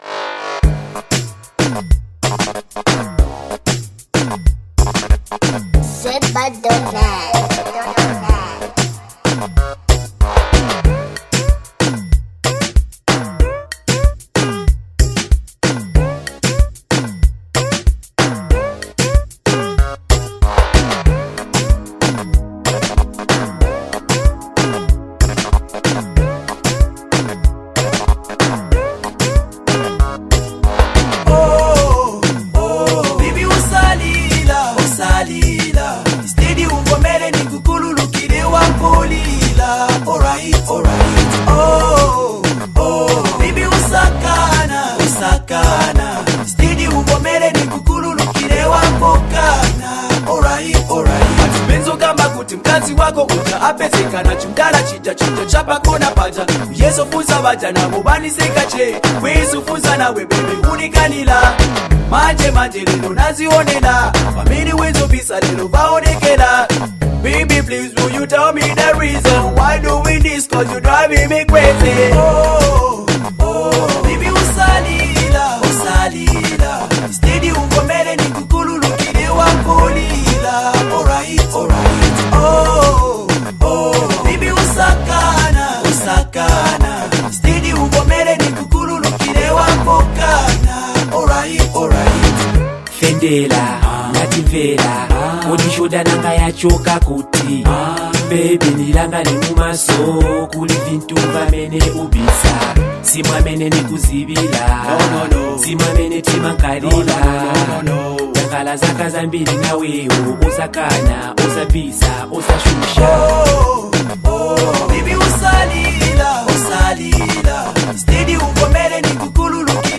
complementing the easygoing instrumental backdrop.